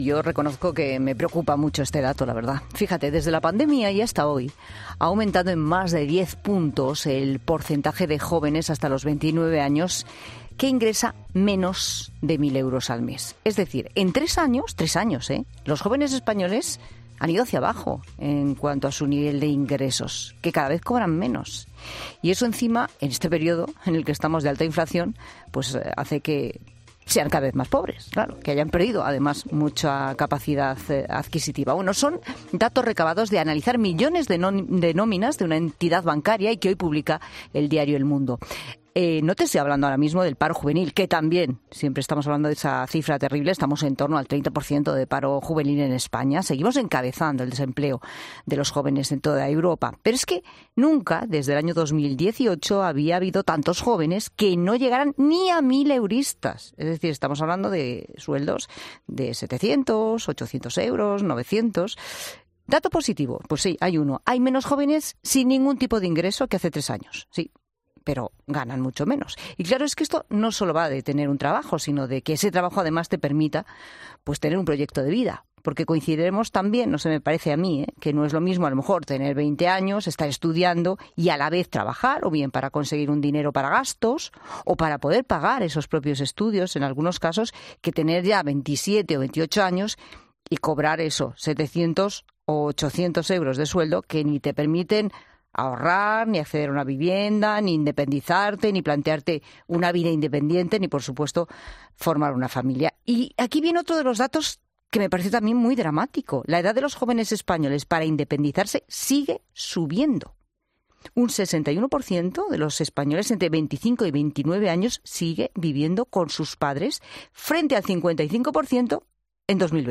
El economista Fernando Trías de Bes ha pasado por los micrófonos de La Tarde para analizar los últimos datos sobre empleo juvenil y para dar las claves a los jóvenes de qué pueden hacer para reconducir la situación.